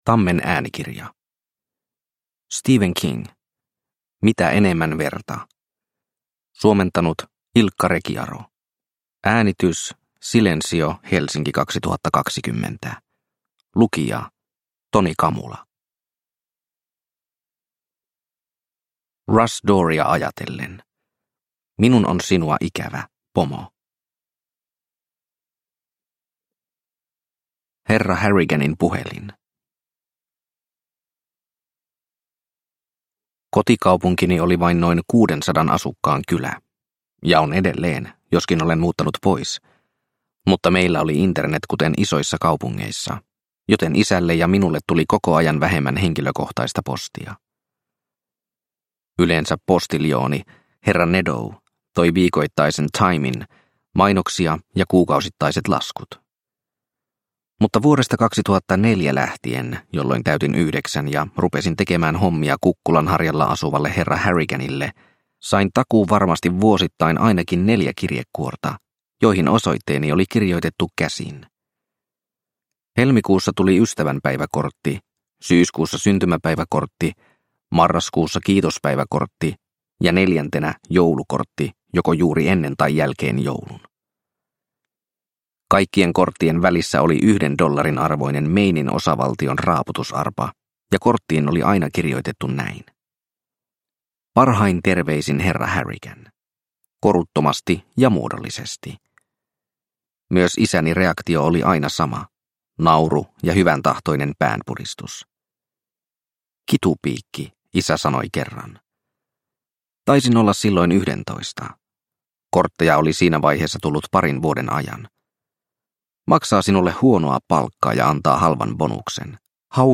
Mitä enemmän verta – Ljudbok